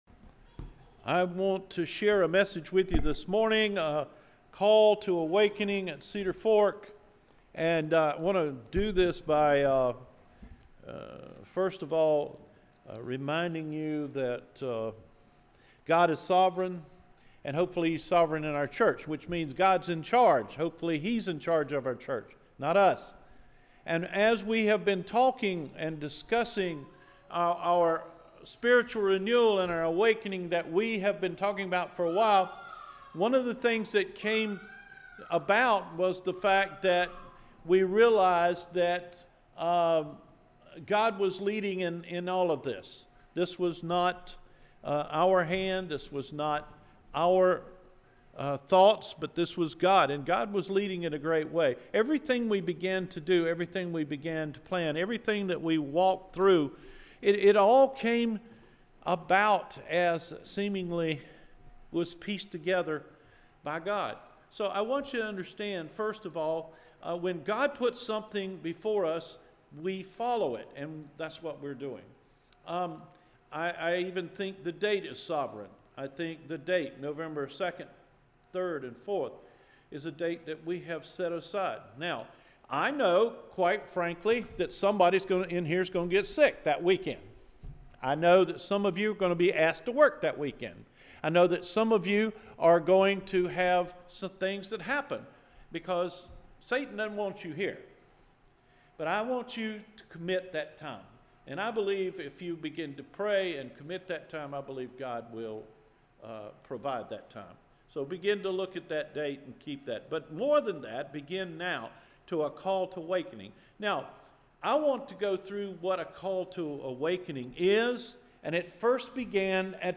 Call to Awakening – September 9 Sermon